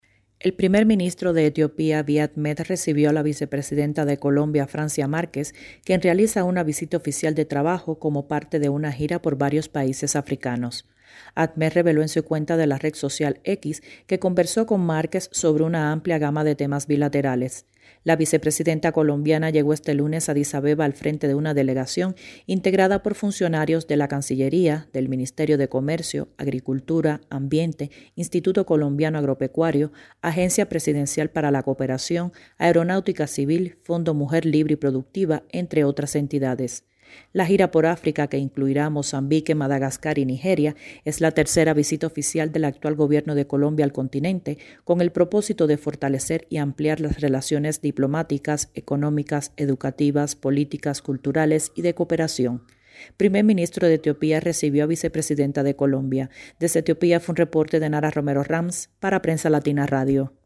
desde Etiopía